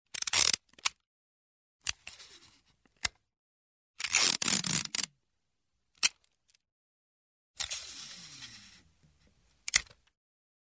Звуки скотча